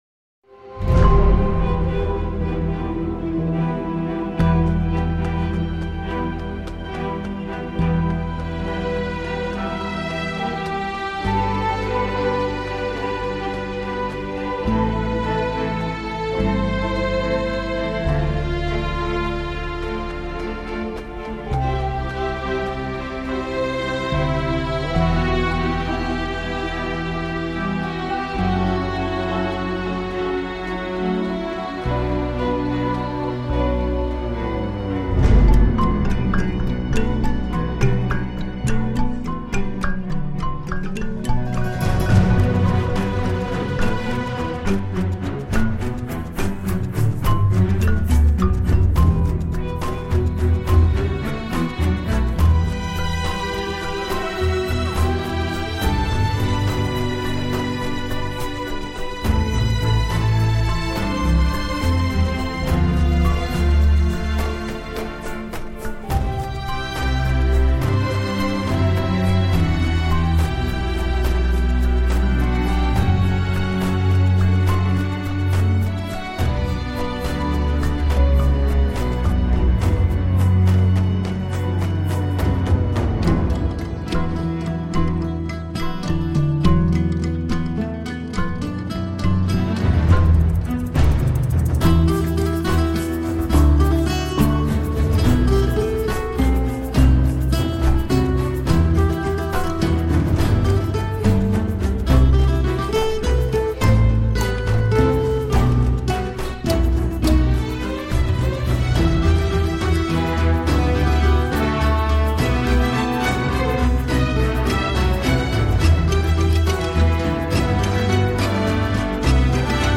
سبک موسیقی (Genre) موسیقی متن, موسیقی بازی